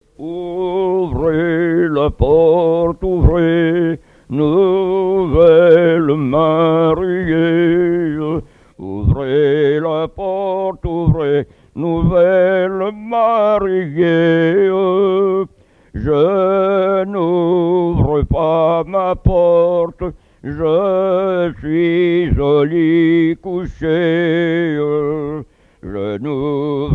Genre strophique
collecte en Vendée
Pièce musicale inédite